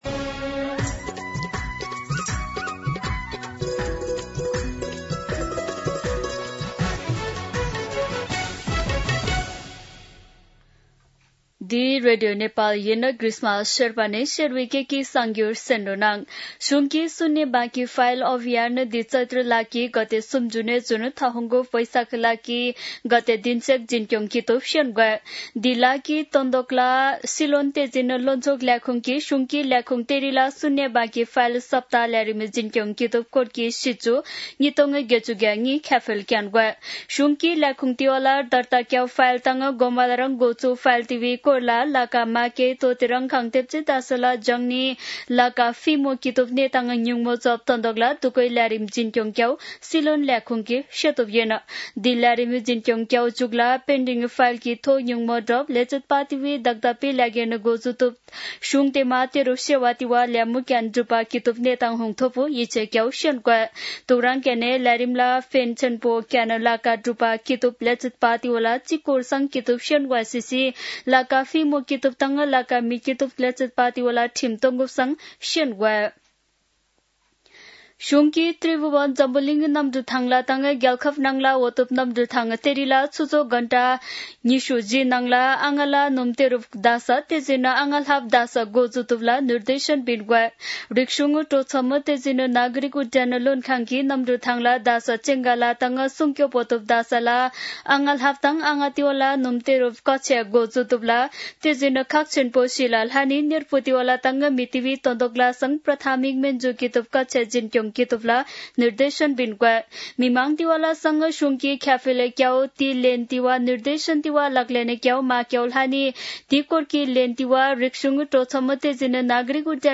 शेर्पा भाषाको समाचार : २८ चैत , २०८२
Sherpa-News-28.mp3